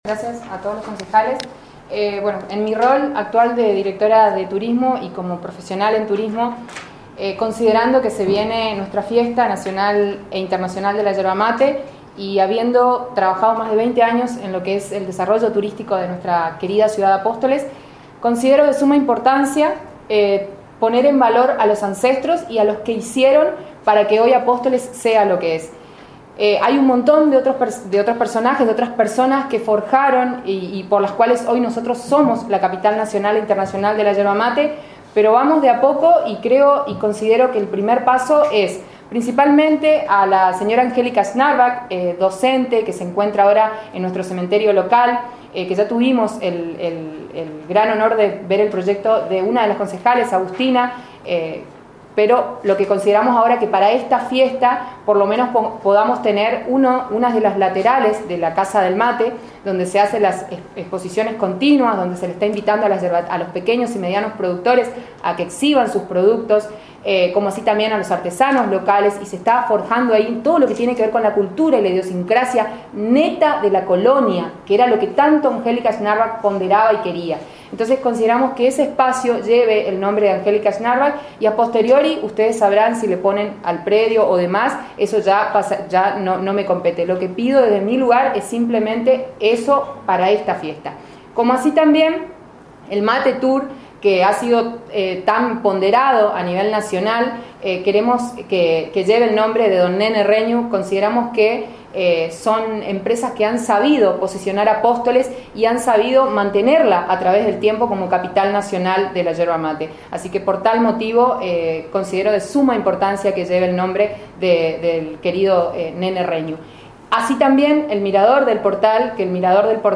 En un cuarto intermedio solicitado por el Pdte. del Cuerpo Deliberativo, la directora de Turismo Municipal Avelina Vizcaychipi, mencionó personas de esta localidad, relacionadas directamente con la yerba mate, a las que desean homenajear desde su cartera colocándoles sus nombres a lugares y actividades que desarrollan en el municipio.